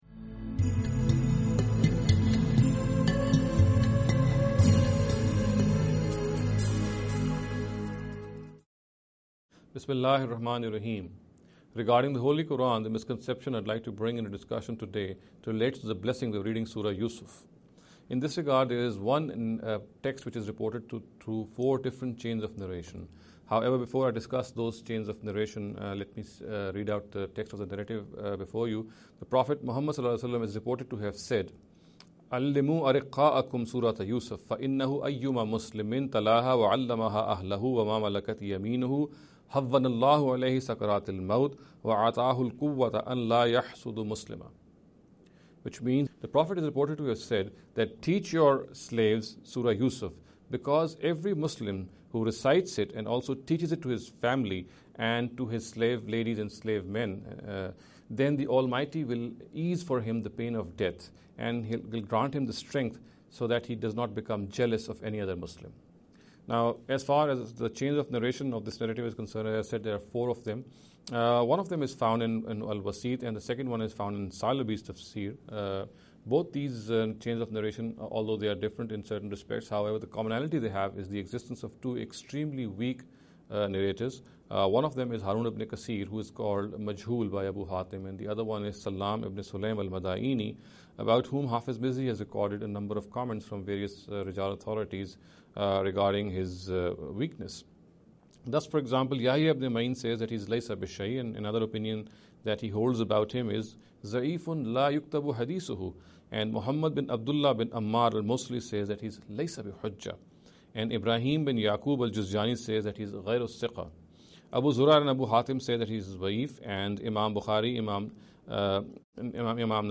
This lecture series will deal with some misconception regarding the Holy Quran.